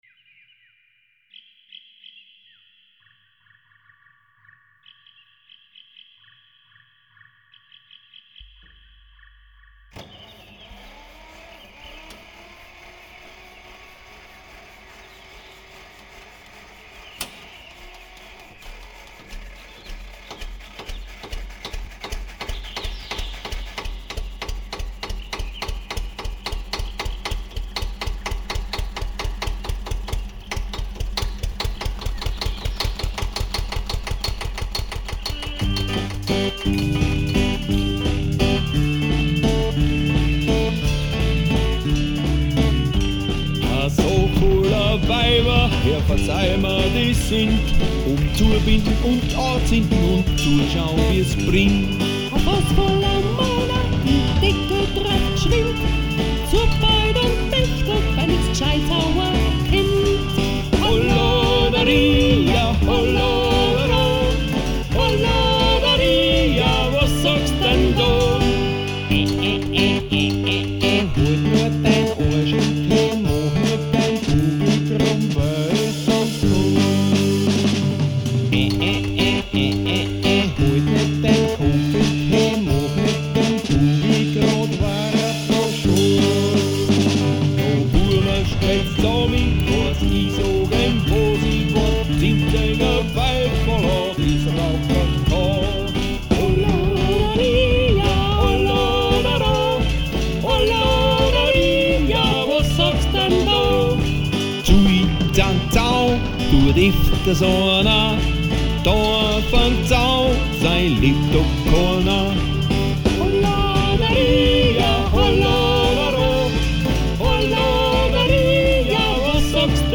A traditional, very traditionally!